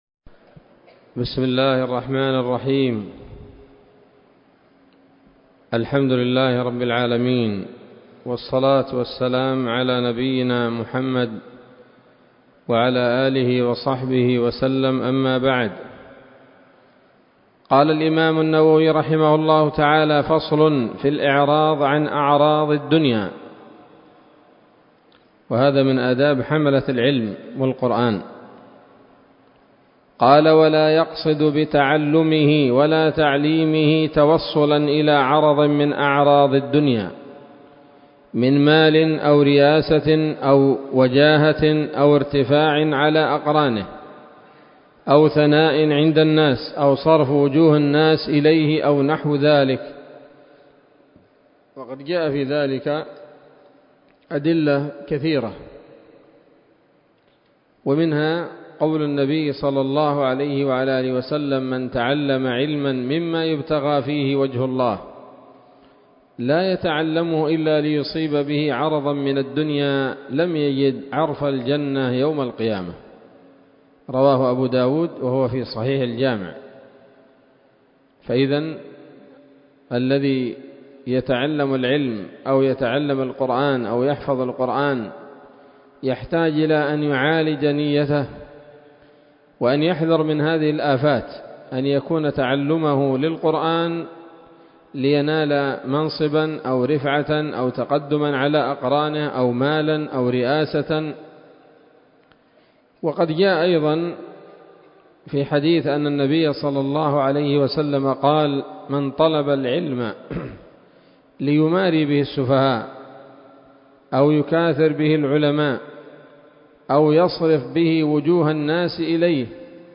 الدرس الخامس من مختصر التبيان في آداب حملة القرآن للنووي